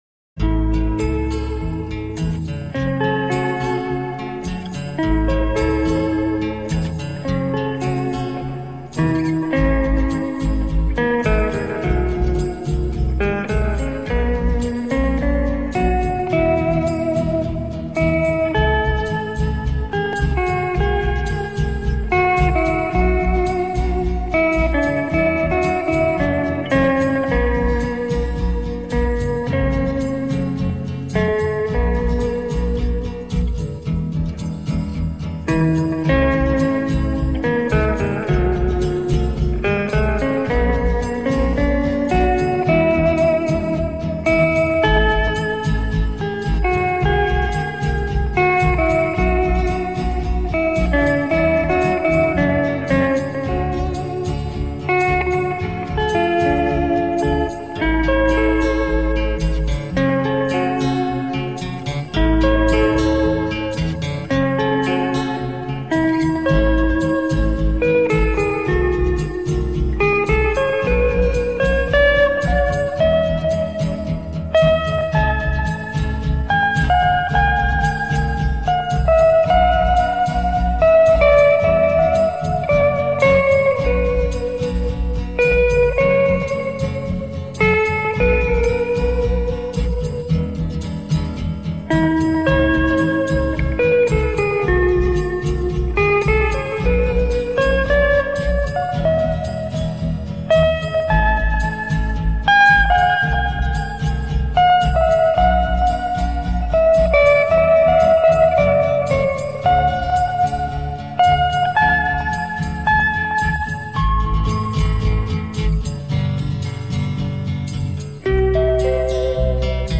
没有花巧的配乐，没有激情的技巧，简简单单的，静静地带着伤感的一首